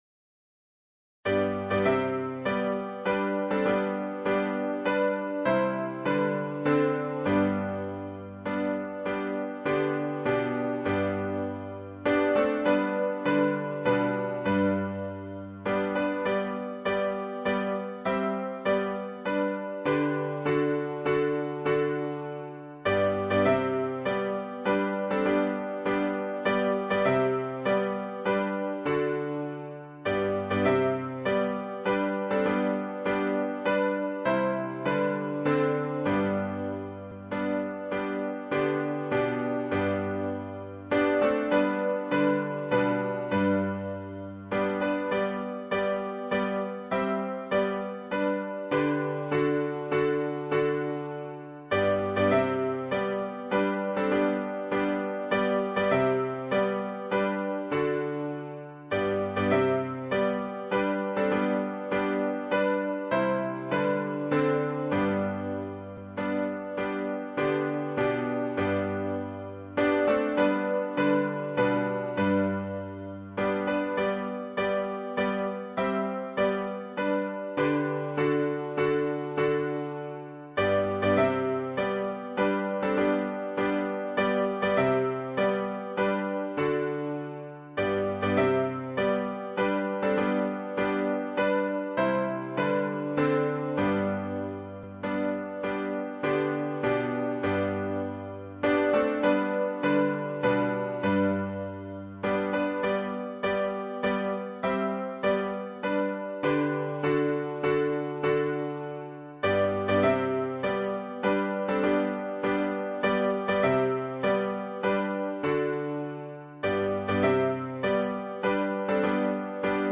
伴奏
原唱音频